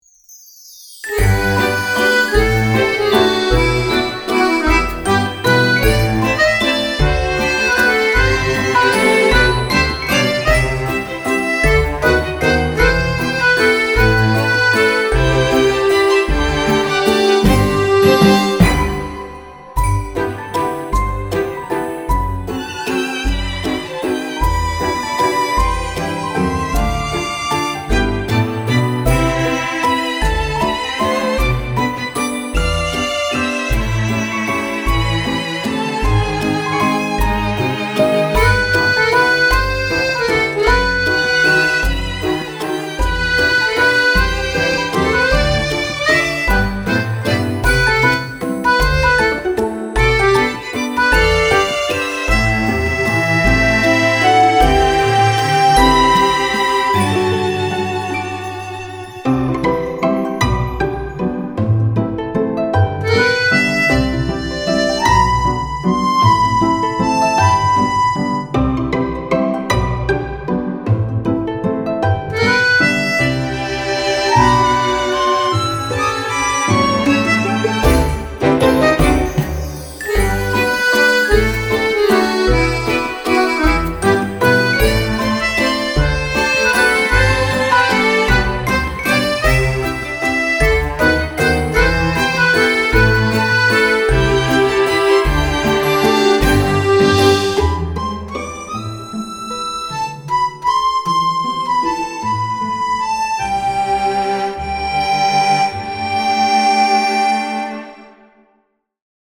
人生や仕事に疲れた人の心を癒やしてくれる優しいBGM。
BPM 155